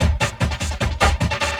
45 LOOP 03-L.wav